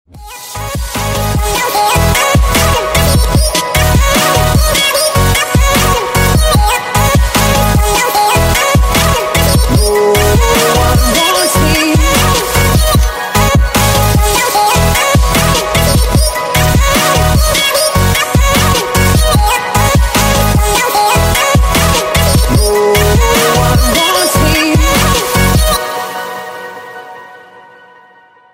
Рингтоны Ремиксы
Рингтоны Электроника